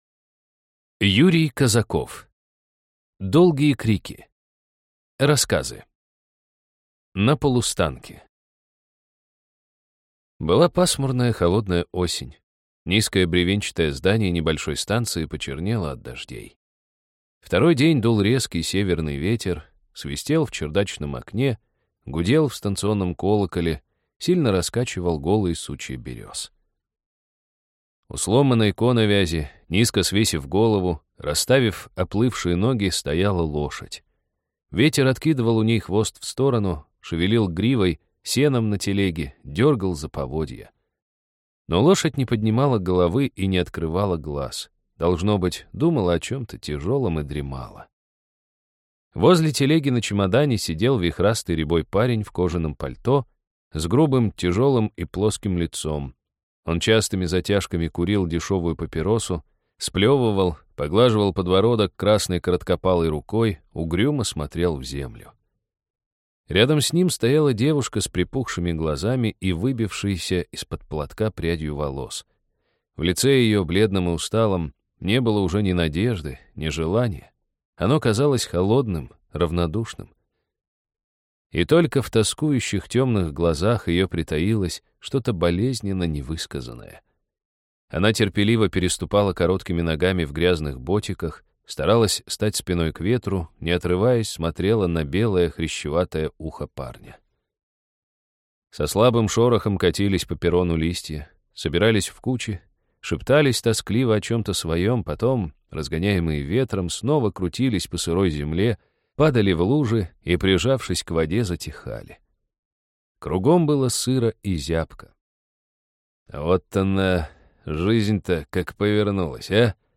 Аудиокнига Долгие крики | Библиотека аудиокниг
Прослушать и бесплатно скачать фрагмент аудиокниги